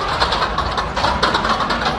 grasping_hands.ogg